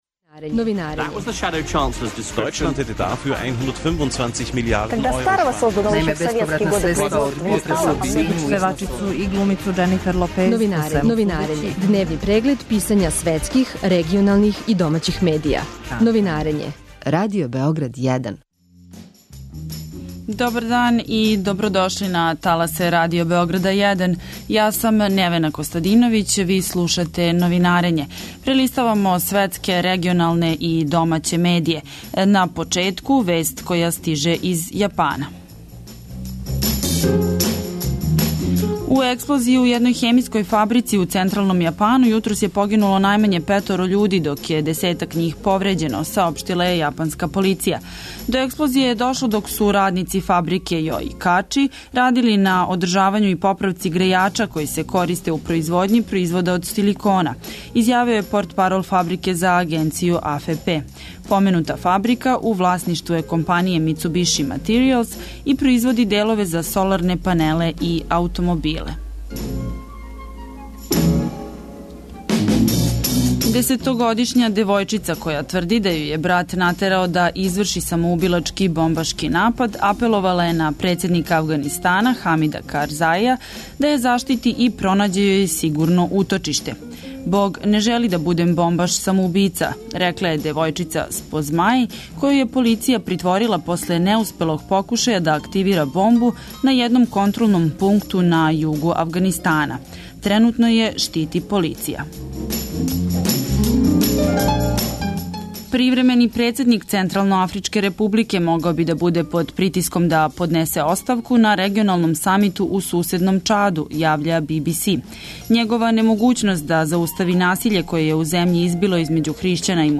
Радио Београд 1, 09.40